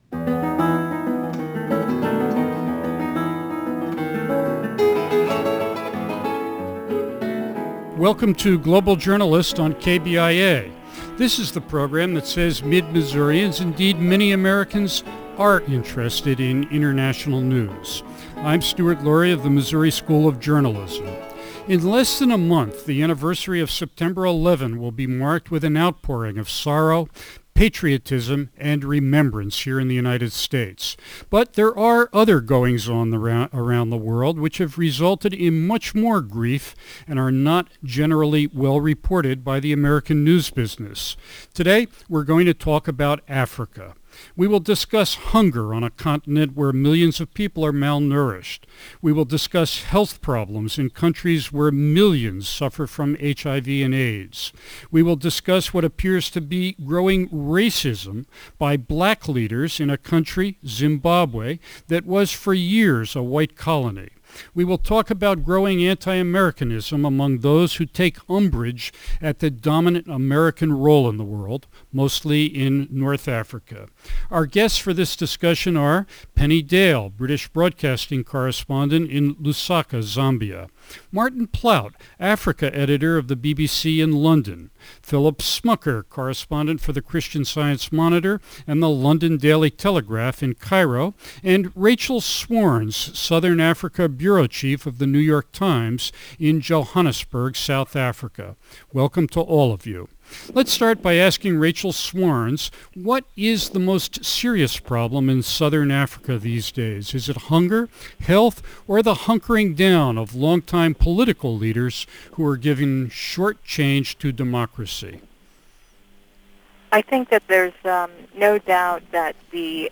The five journalists also discuss the growing wave of anti-Americanism in Africa.